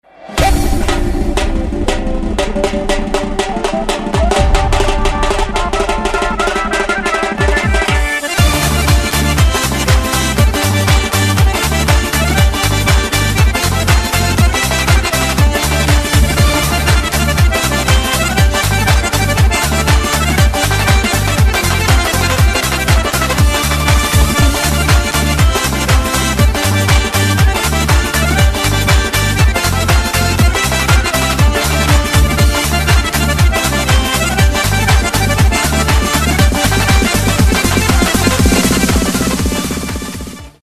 • Качество: 128, Stereo
без слов
Народные
быстрые
кавказские
Гармошка
этнические
барабан